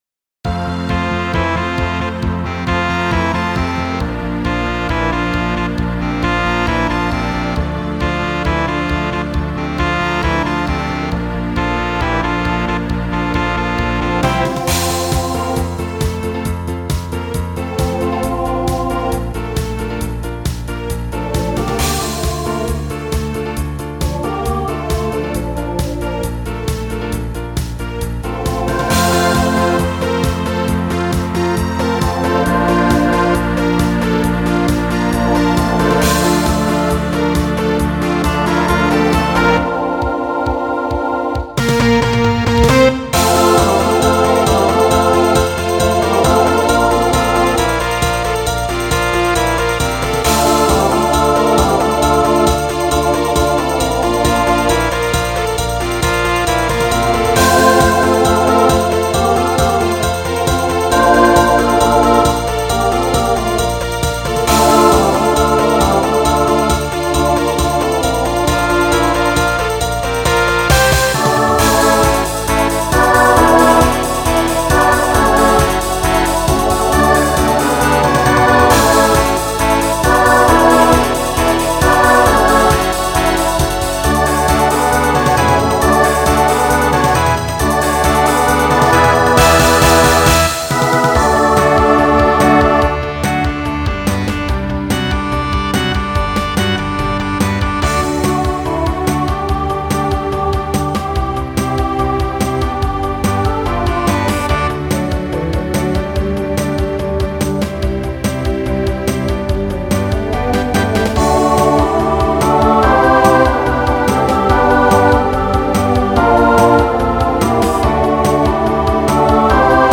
Voicing SATB Instrumental combo Genre Pop/Dance , Rock